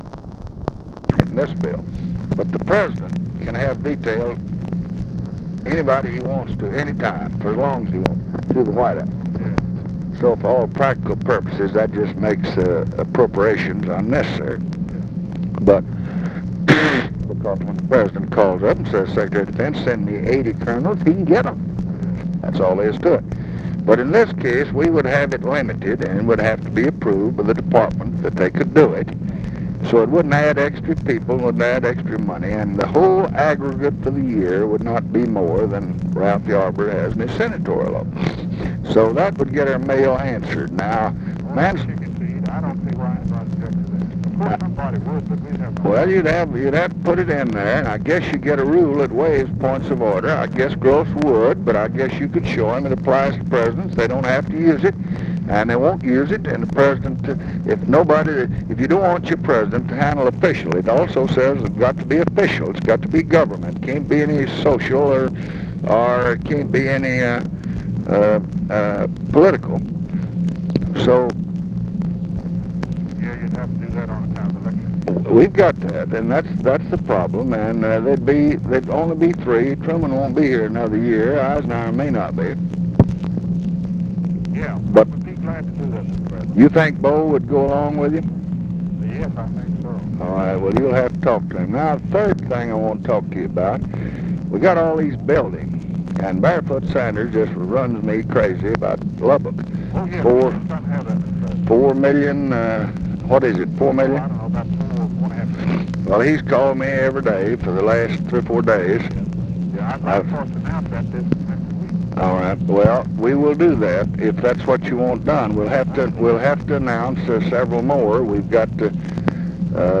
Conversation with GEORGE MAHON, November 16, 1968
Secret White House Tapes